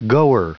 Prononciation du mot goer en anglais (fichier audio)
Prononciation du mot : goer